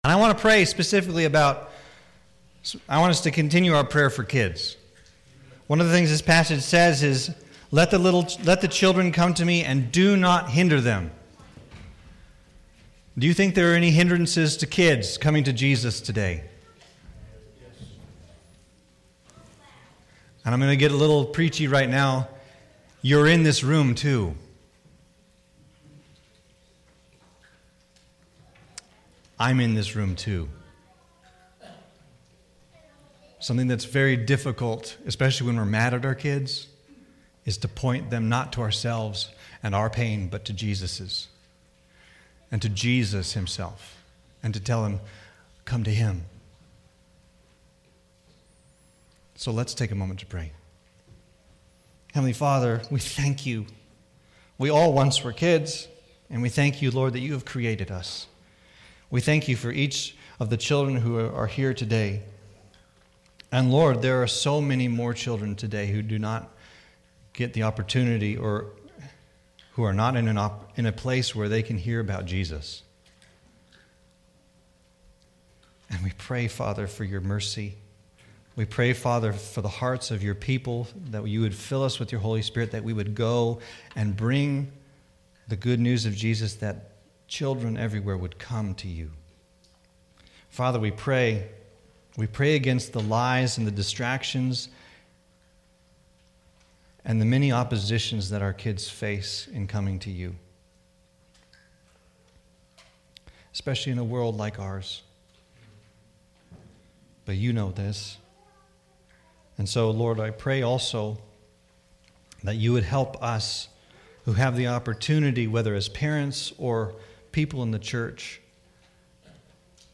Sermons | York Evangelical Free Church